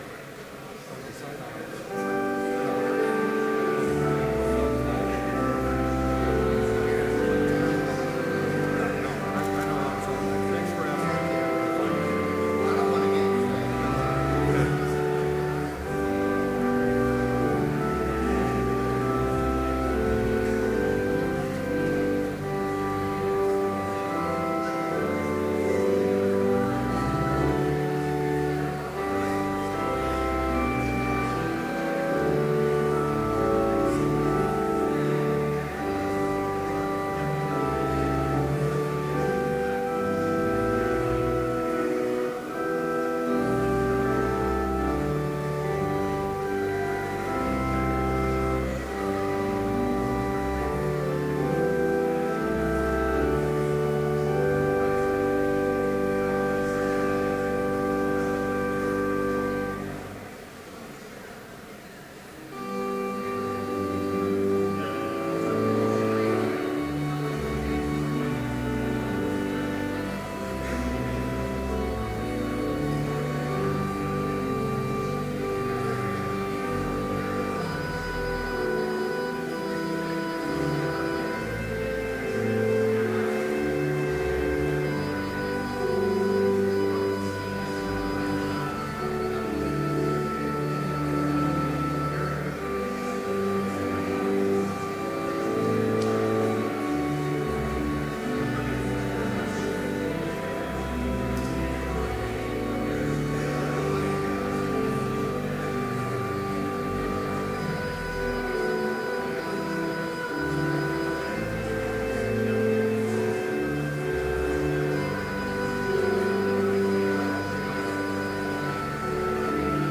Complete service audio for Chapel - September 13, 2012